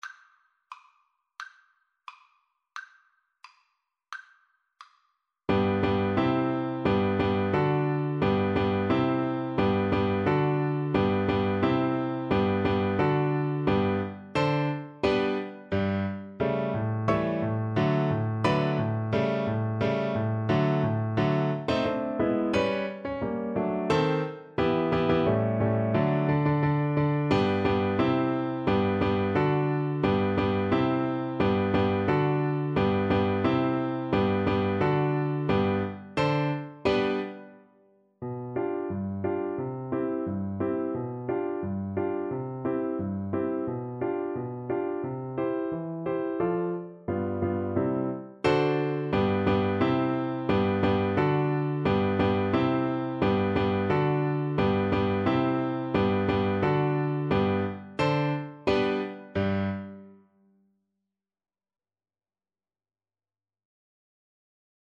~ = 88 Stately =c.88
G major (Sounding Pitch) (View more G major Music for Flute )
2/4 (View more 2/4 Music)
Classical (View more Classical Flute Music)